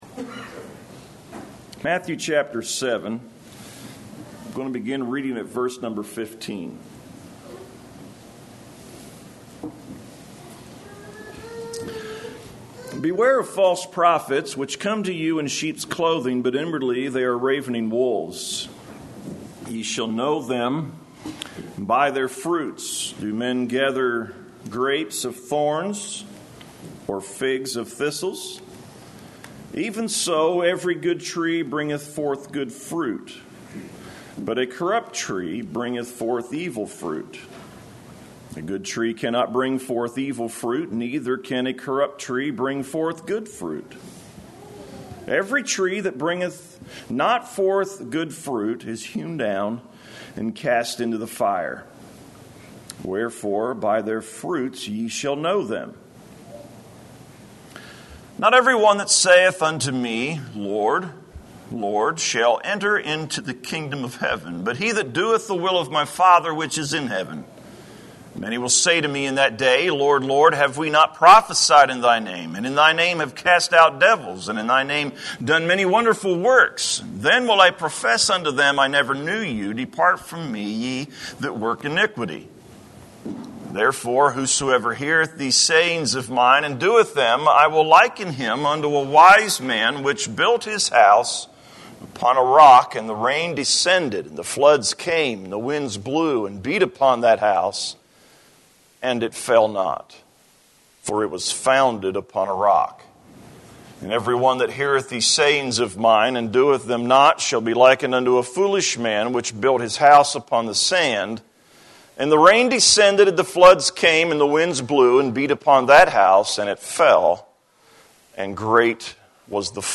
Save Audio A message